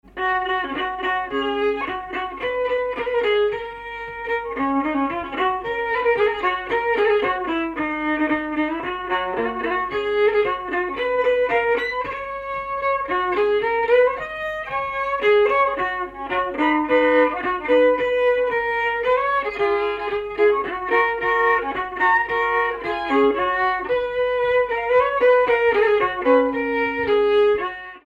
Danse
Ugine
circonstance : bal, dancerie ;
Pièce musicale inédite